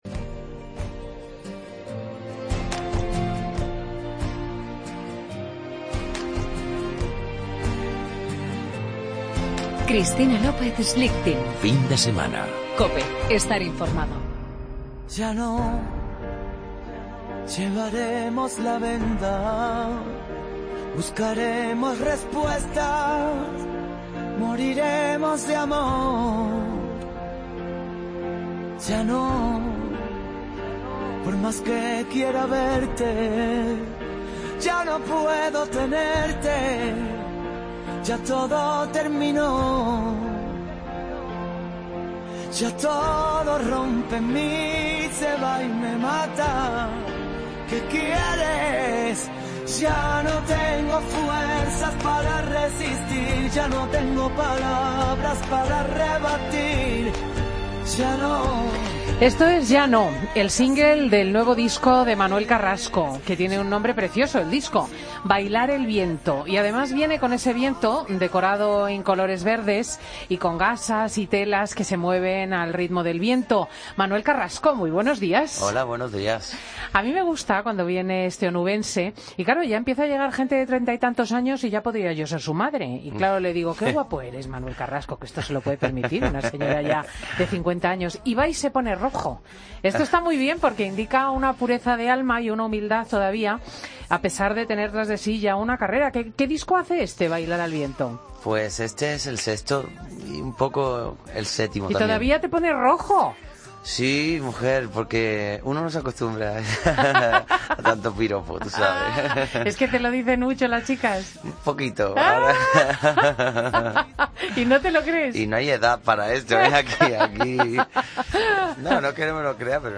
Entrevista a Manuel Carrasco en Fin de Semana Cope